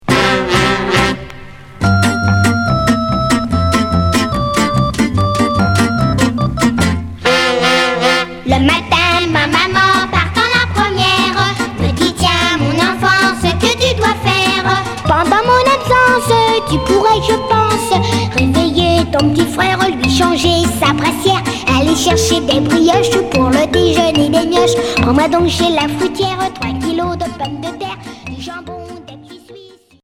Pop enfantine